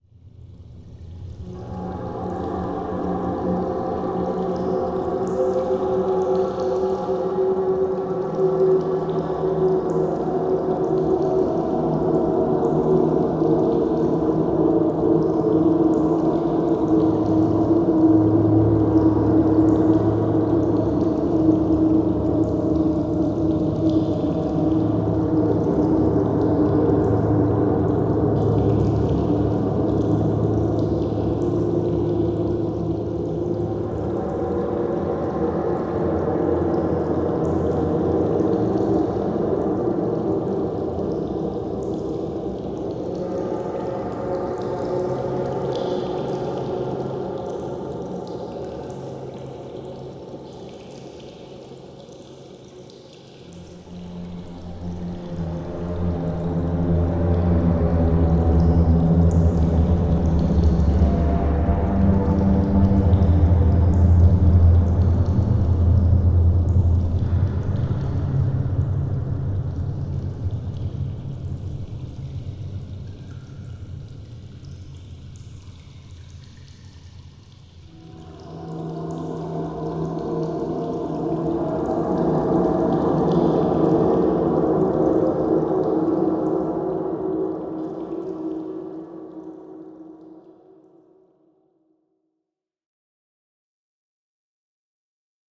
Ощутите загадочную атмосферу пещер с нашей коллекцией натуральных звуков.
Пещера - Жуткий звук пещеры, словно ты внутри